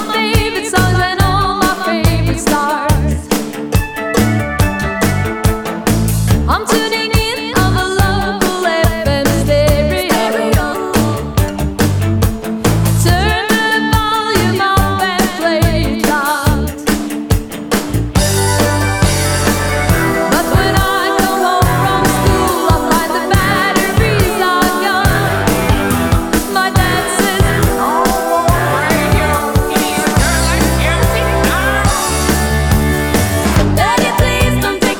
Adult Contemporary Pop
Жанр: Поп музыка